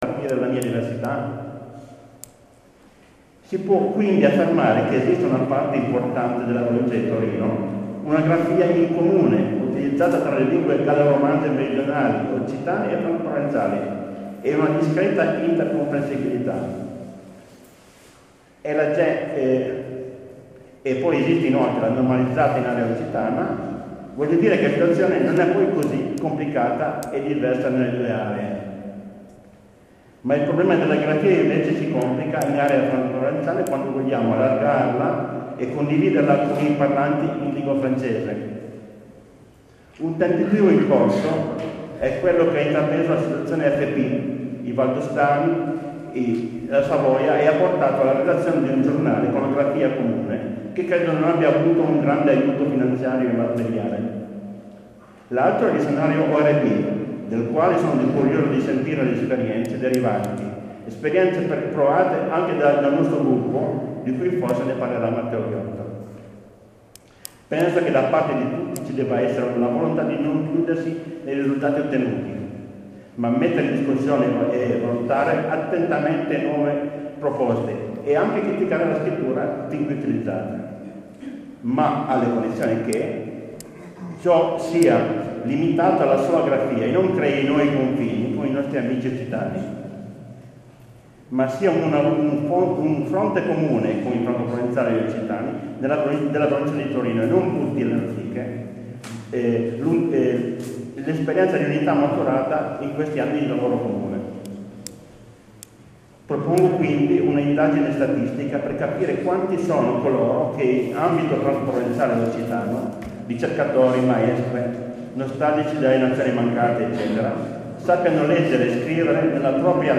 Susa ORB 2.0 : Salutacions des ôtoritâts
Sendeca du comon de Susa, en Piémont, doctorèssa Gemma Amprino
Assèssor a la cultura por la provença de Turin : Ugo Perrone
Vice-prèsident du Consèly de la règion Piémont : Gianfranco Modeno
President de la Comunitât Montana Val Susa et Sangone